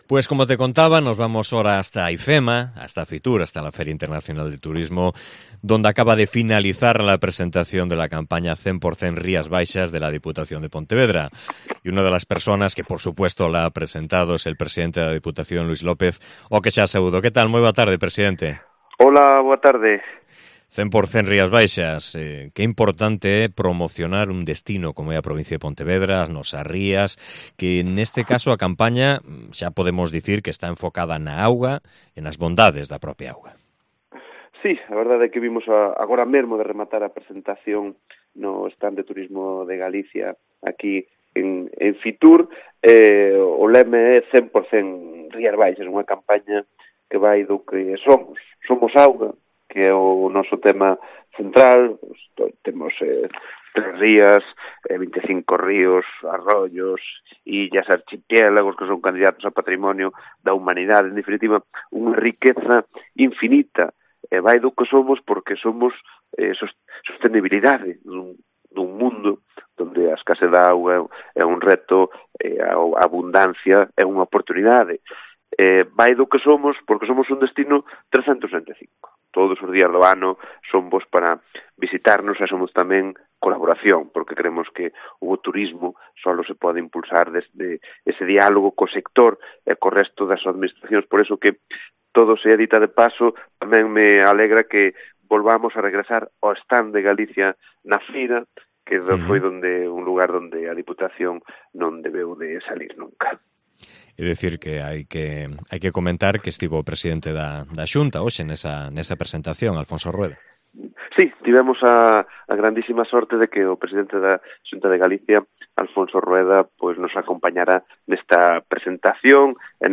Hablamos con su presidente, Luis López, sobre una campaña de promoción centrada en el agua y en el resto de atractivos de nuestros concellos